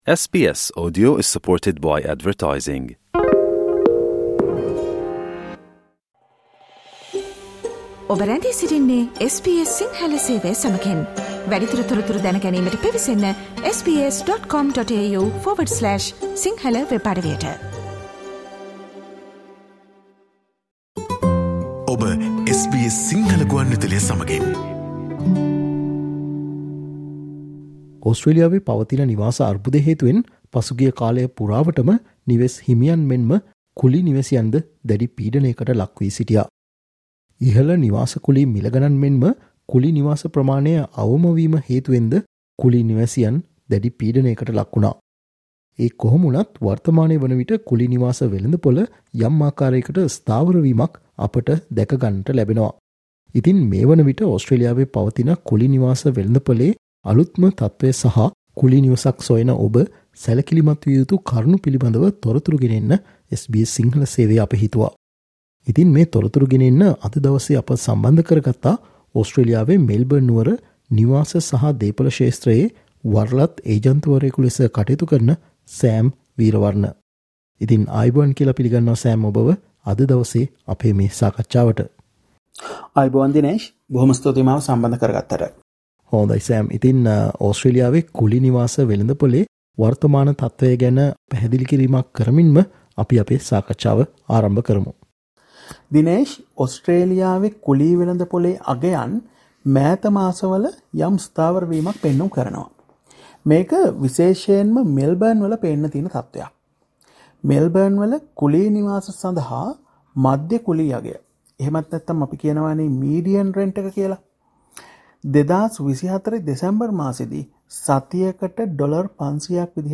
However the rental market is softening right now bringing some relief for renters. Listen to SBS Sinhala discussion on the current state of the rental market in Australia.